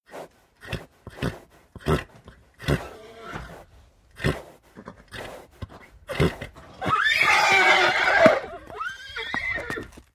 Звук дышащего молодого жеребца